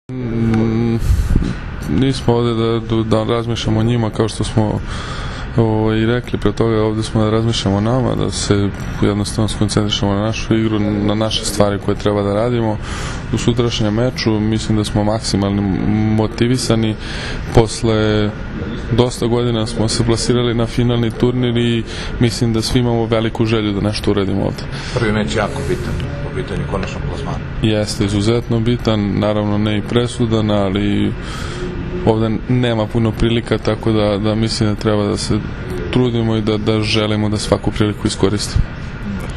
IZJAVA NIKOLE ROSIĆA